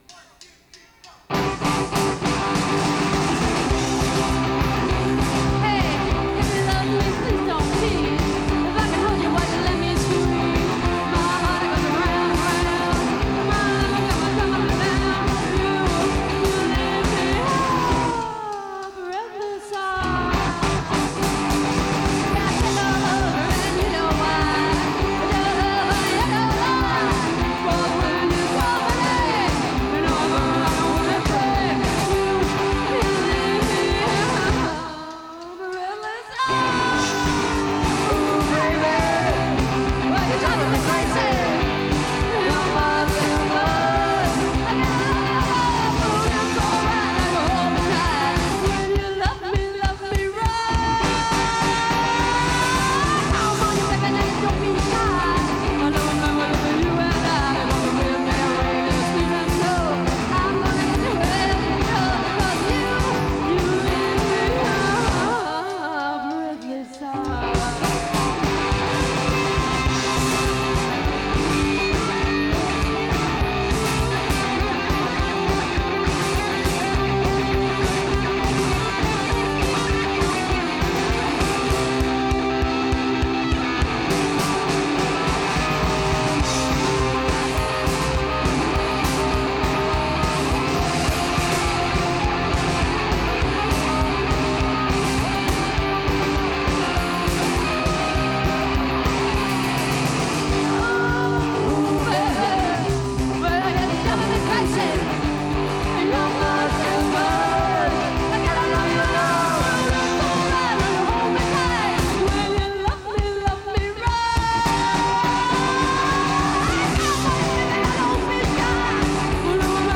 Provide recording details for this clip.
Live from the Cabaret in July 1986.